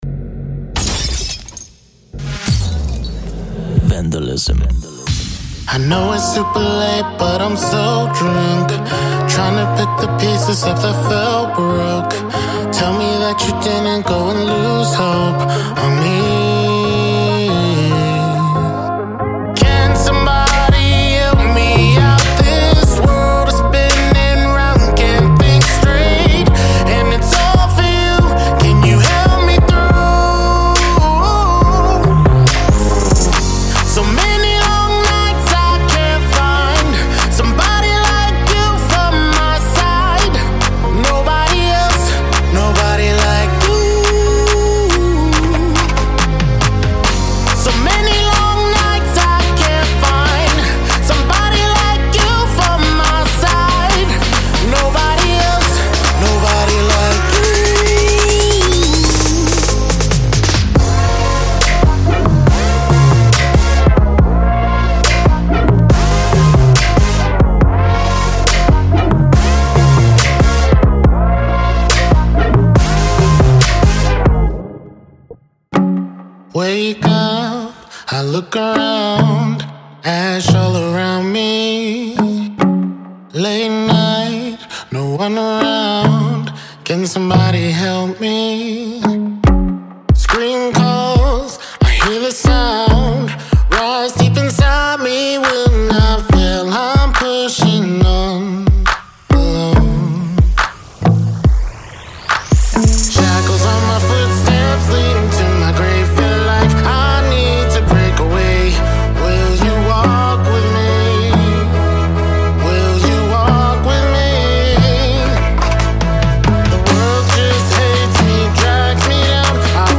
收录了4位由才华横溢的男歌手演唱的优美歌曲。
每首歌曲都包含完全混合的人声，这些人声已100%准备立即在您的曲目中使用。
13x(干）-人声短语
.04x(湿)-声乐短语
·完全混合并掌握